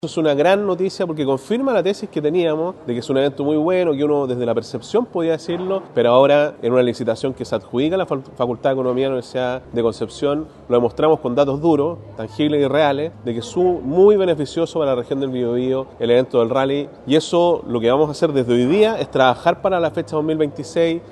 El gobernador regional, Sergio Giacaman, destacó la importancia de los antecedentes que reveló el estudio encargado a la Universidad de Concepción, y que confirmó la sensación que había sobre el impacto económico positivo que tiene el Rally Mundial para el Bío Bío.
gobernador-rally.mp3